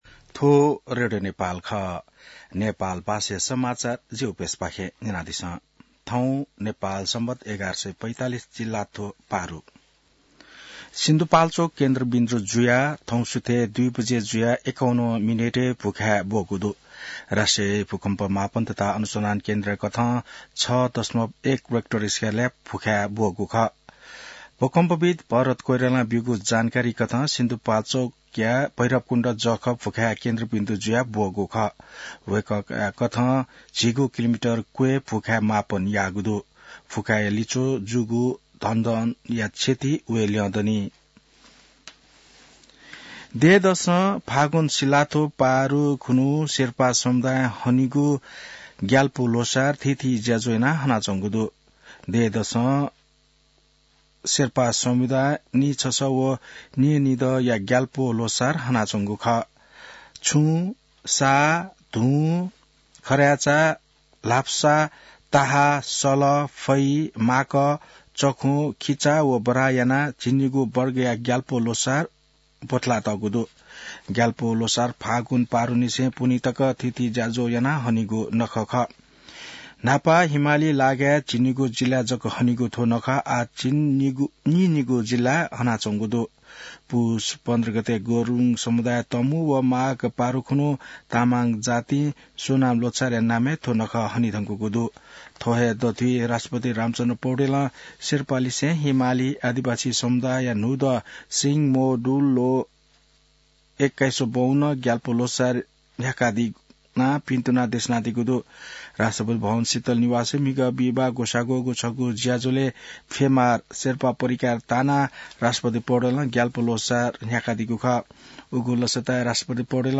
नेपाल भाषामा समाचार : १७ फागुन , २०८१